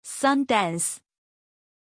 Aussprache von Sundance
pronunciation-sundance-zh.mp3